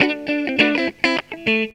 GTR 4  AM.wav